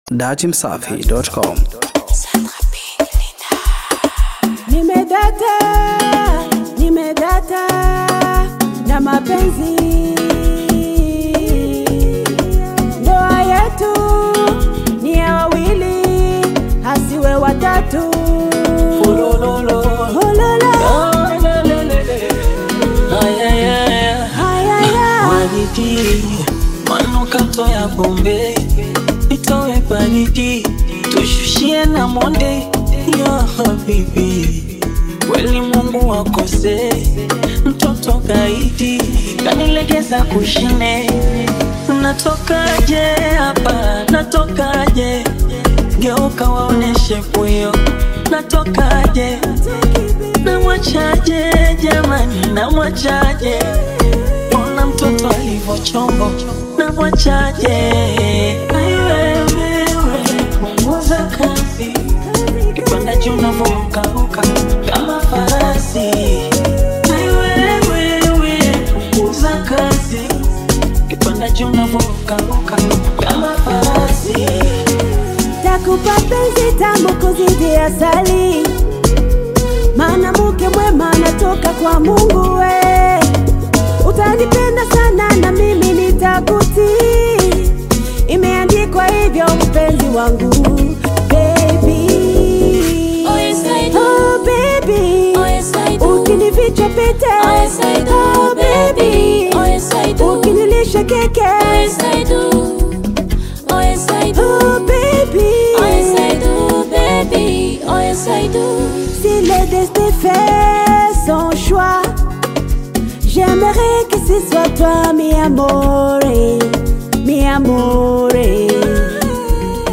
Amapiano